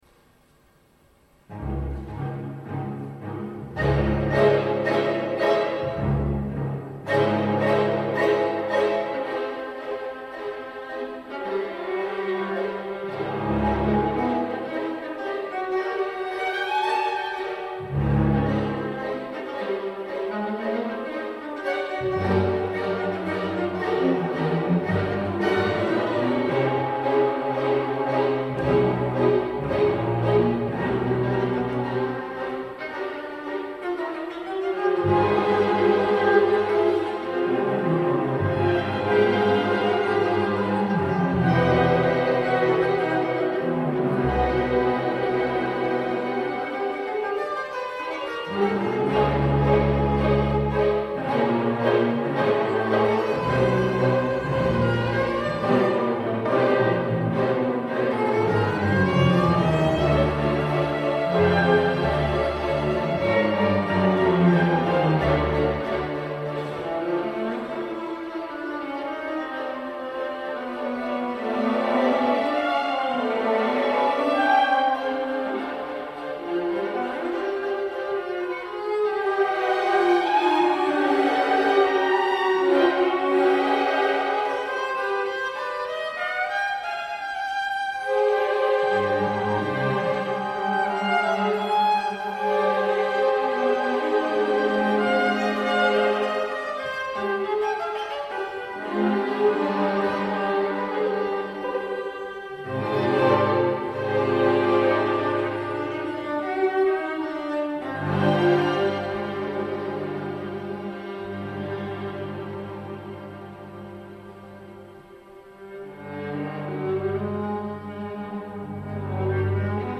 chant élégiaque pour alto, orchestre à cordes et clavecin